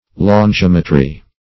longimetry - definition of longimetry - synonyms, pronunciation, spelling from Free Dictionary
Search Result for " longimetry" : The Collaborative International Dictionary of English v.0.48: Longimetry \Lon*gim"e*try\, n. [L. longus long + -metry: cf. F. longim['e]trie.] The art or practice of measuring distances or lengths.